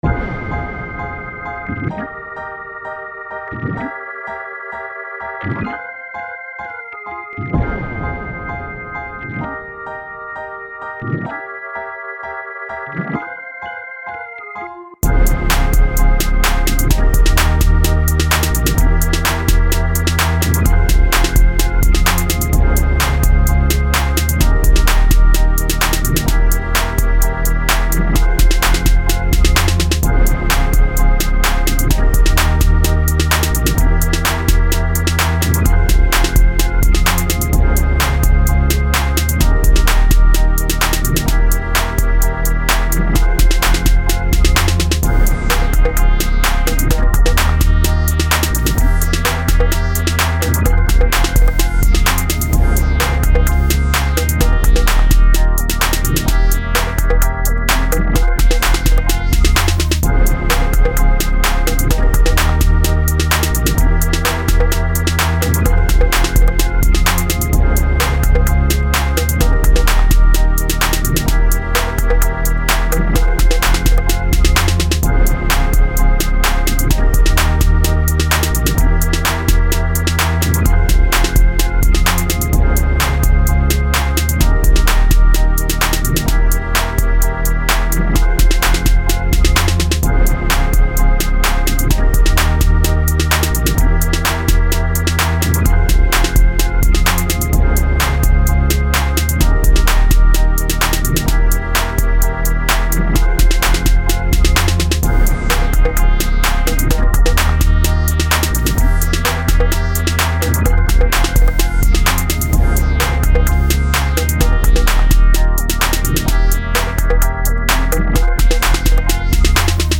오르간이랑 피아노 소리를 샘플씨디에서 따왔는데 방금 드레이크노래 들어봤는데 너무 비슷하네요 젠장 ㅋㅋㅋㅋㅋ